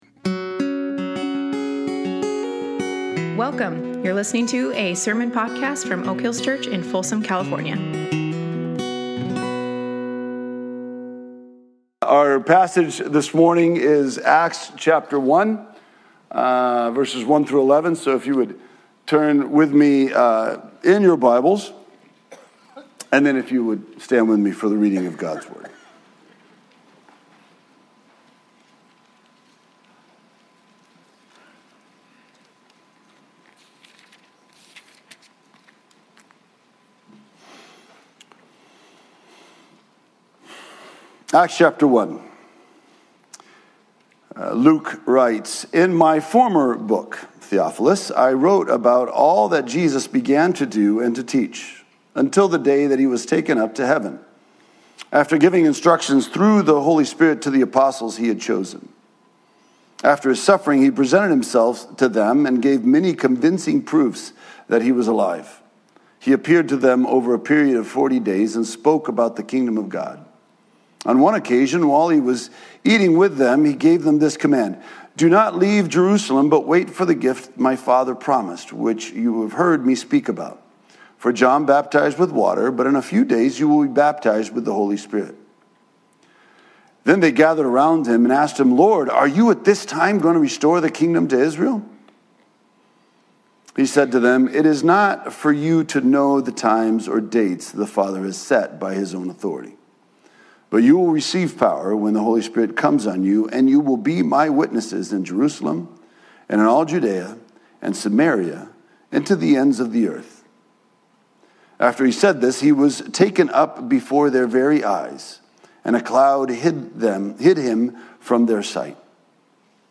Acts 1:1-11 Service Type: Sunday Morning You see